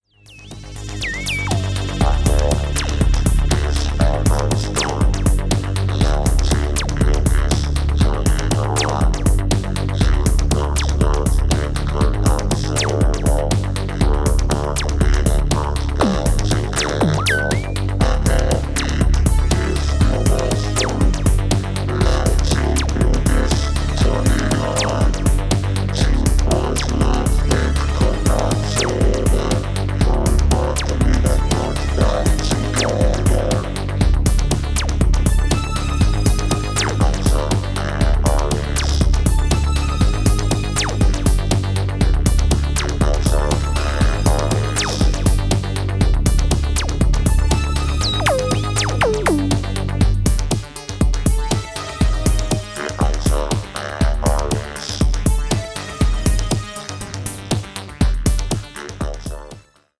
Disco Electro